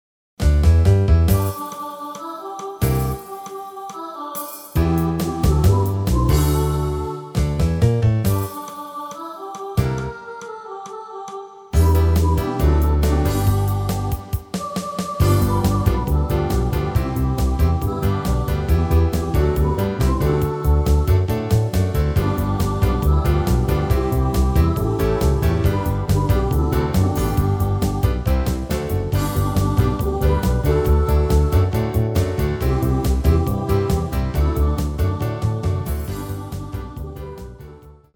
Rubrika: Vánoční písně, koledy